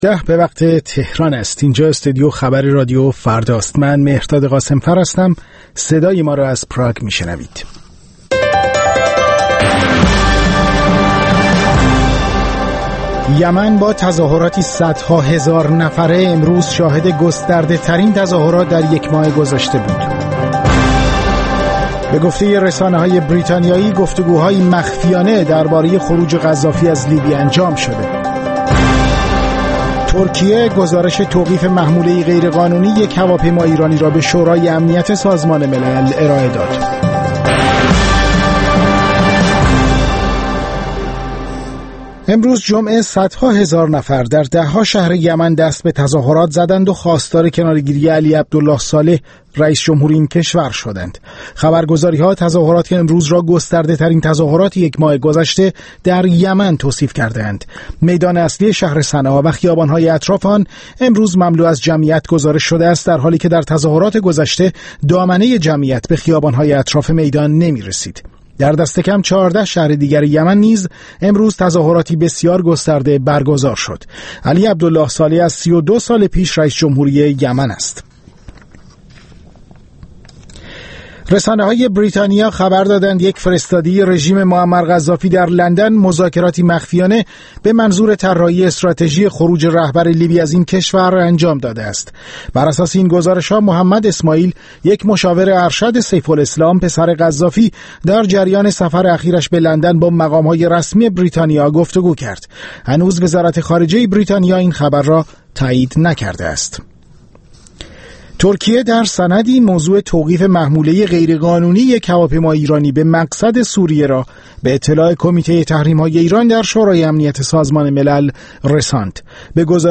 تماس‌های مستقیم شما